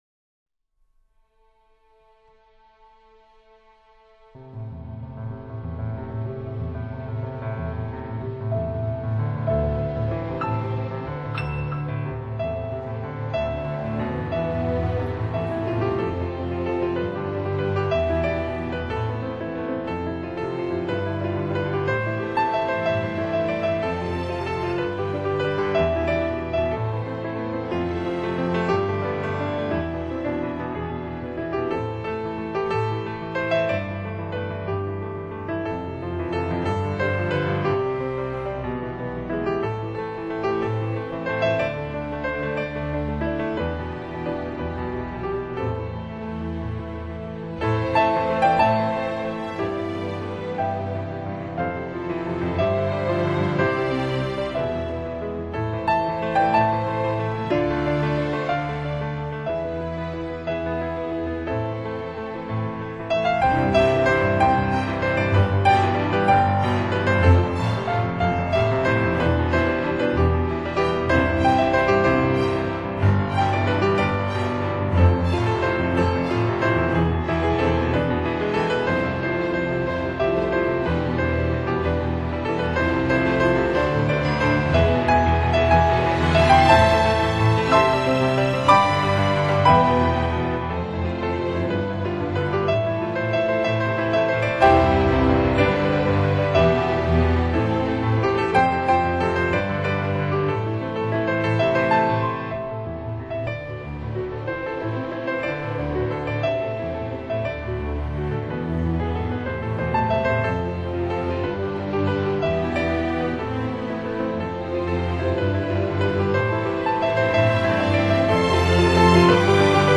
钢琴solo